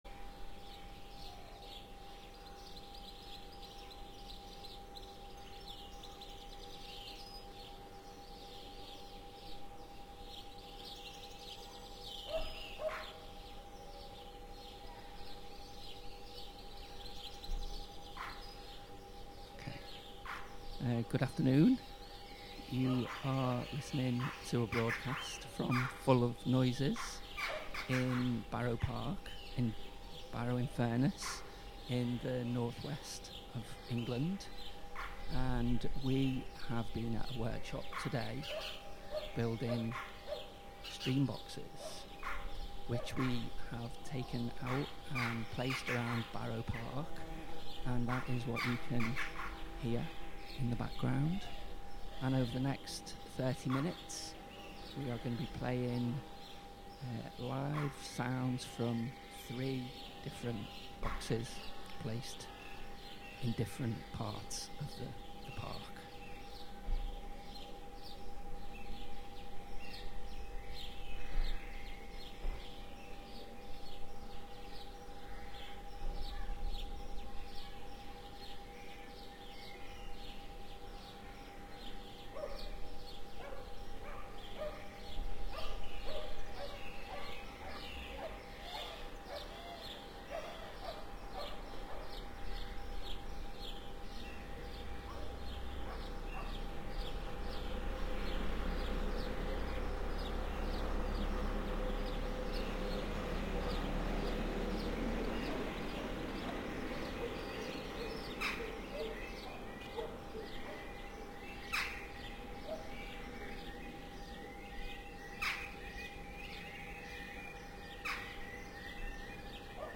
Live from Soundcamp: Ecologial Radio - Full of Noises (Audio) Apr 22, 2023 shows Live from Soundcamp Live from Barrow-in-Furrnes, UK. Participants of an ecological radio workshop present Barrow Park.